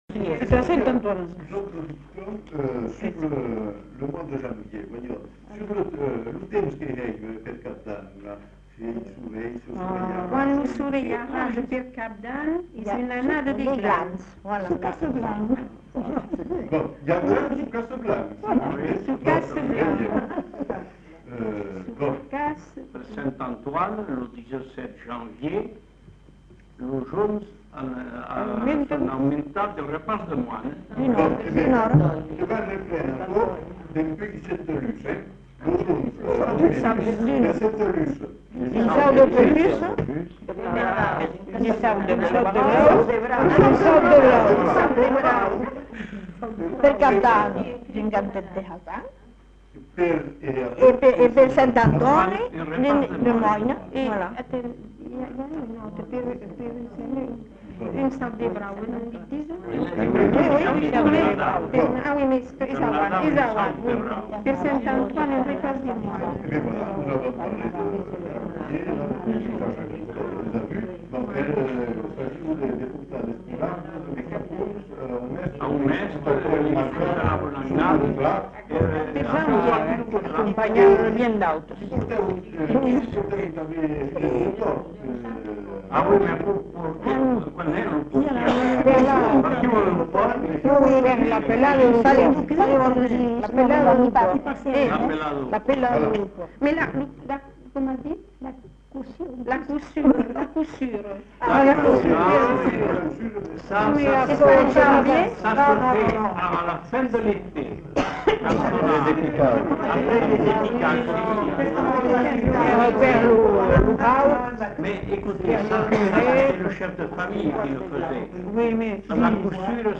Lieu : Bazas
Genre : témoignage thématique
Effectif : 1
Type de voix : voix de femme
Production du son : récité
Classification : proverbe-dicton